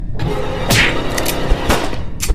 Among Us Death 3 Sound Effect Free Download